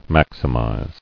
[max·i·mize]